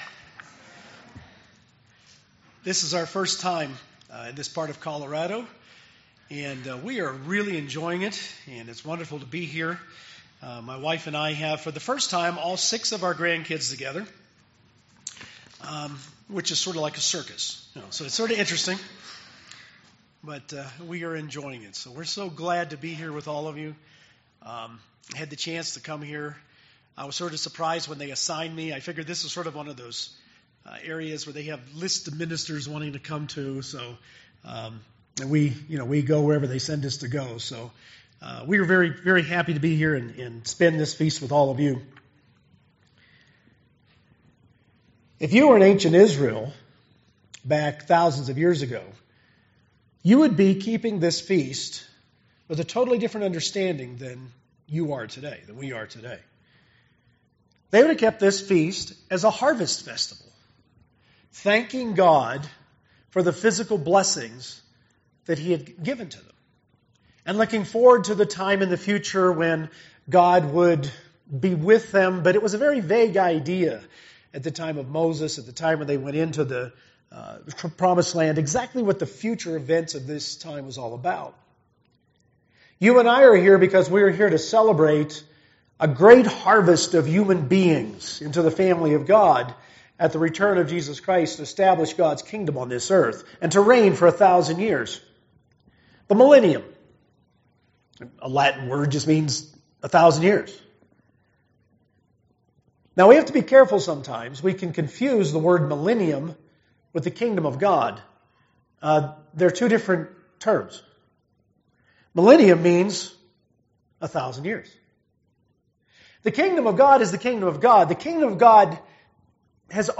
This sermon was given at the Steamboat Springs, Colorado 2017 Feast site.